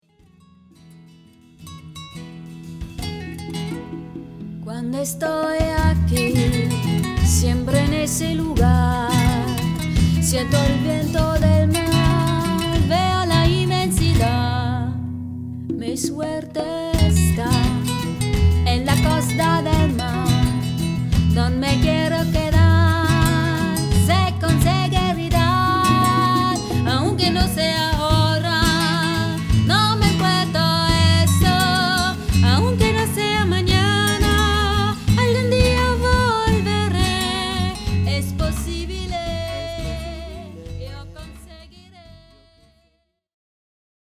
Akustik-Gitarre, Gesang, Palmas
Cajon, Gesang, Palmas
Flamenco-Gitarre, Cajon, Gesang
• Latin/Salsa/Reggae